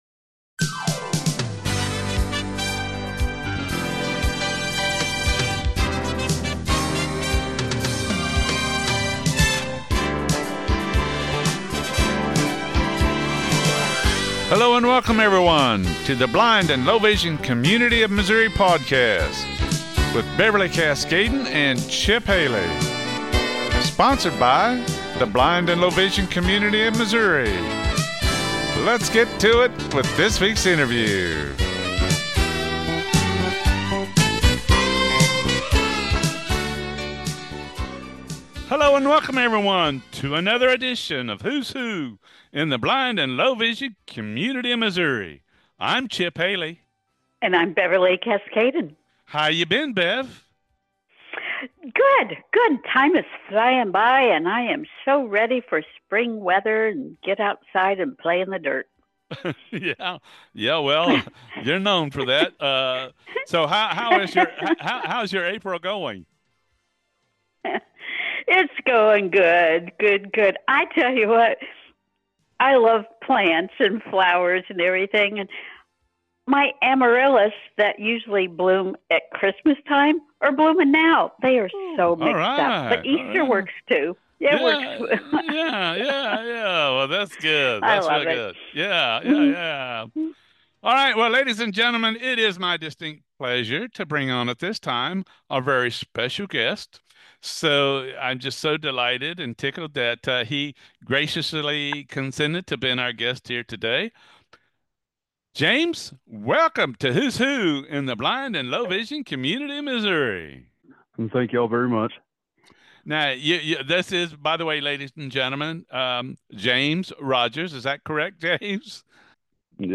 Blind & Low Vision Community Of Missouri